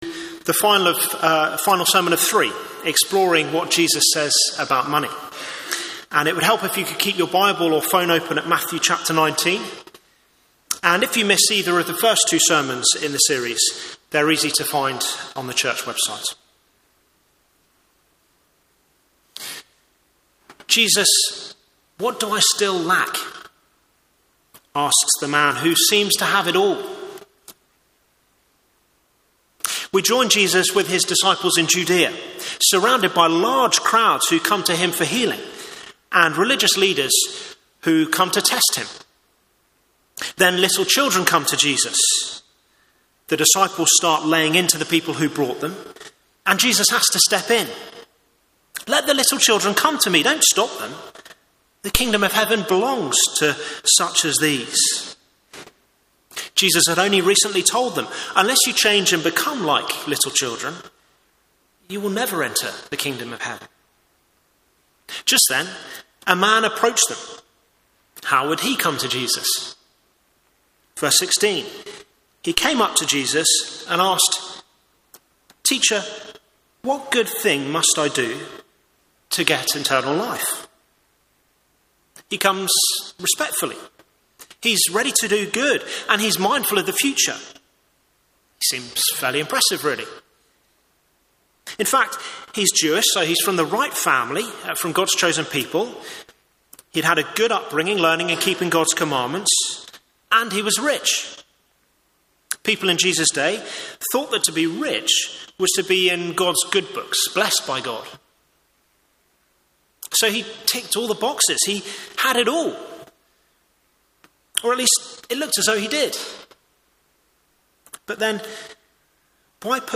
Media for Morning Service on Sun 28th Jan 2024 10:30 Speaker
Passage: Hebrews 13:1-8, Matthew 19:16-30 Series: Money - what does Jesus say? Theme: Sermon In the search box please enter the sermon you are looking for.